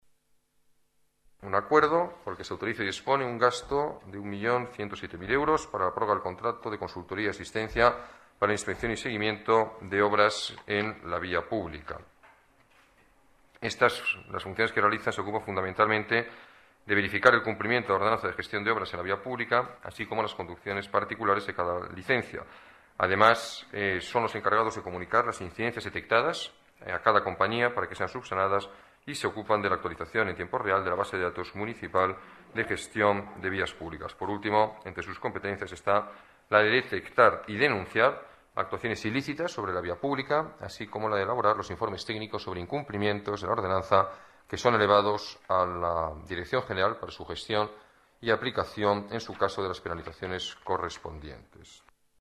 Nueva ventana:Declaraciones del alcalde, Alberto Ruiz-Gallardón